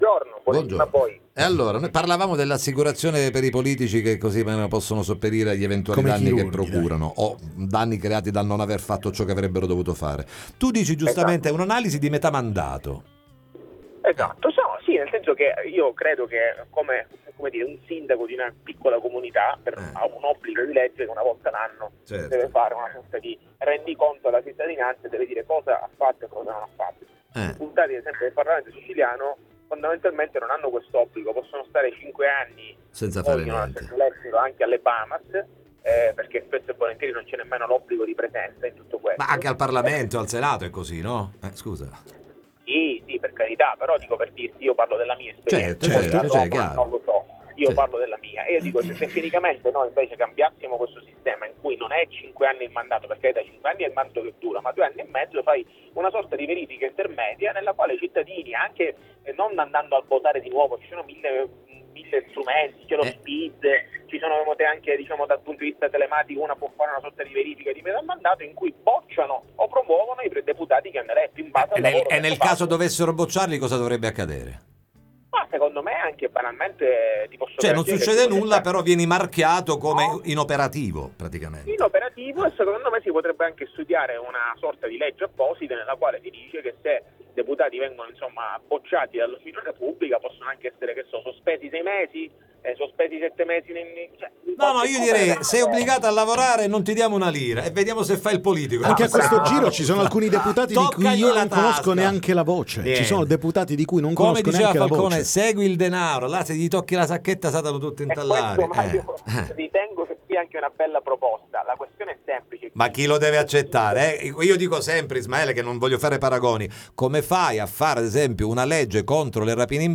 Verifica di metà mandato per i politici siciliani: l’iniziativa lanciata da Ismaele La Vardera, ne parliamo con lui ai ns. microfoni